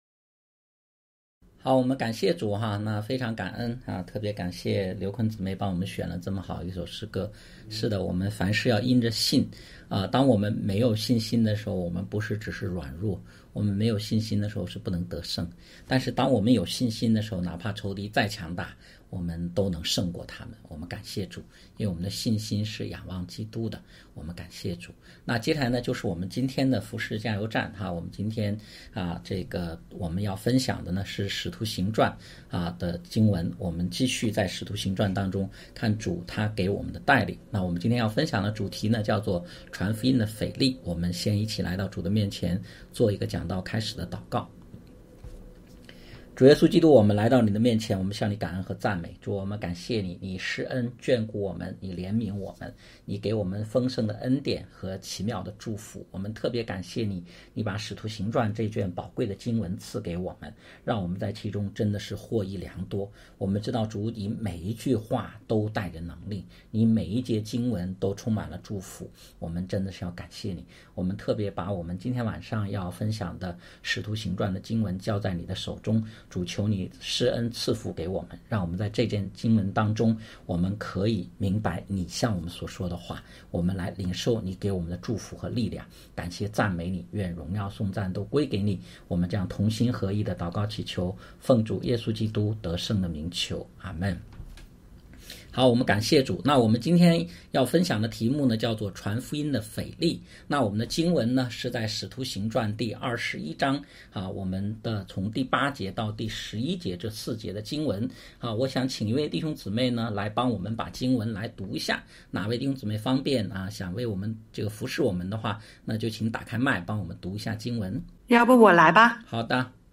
讲道录音 点击音频媒体前面的小三角“►”就可以播放 https